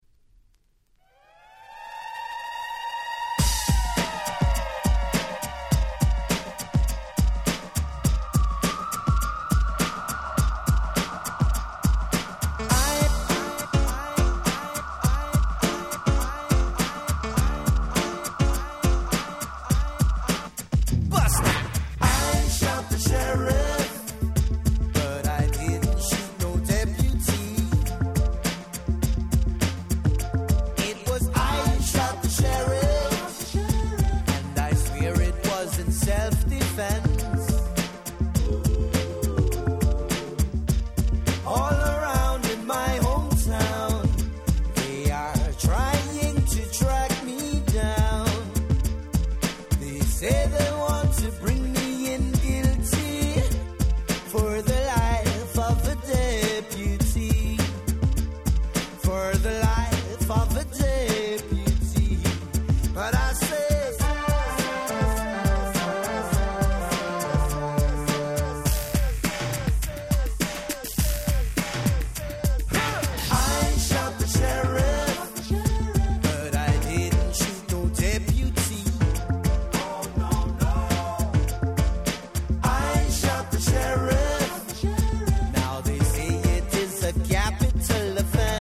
91' Nice Reggae R&B !!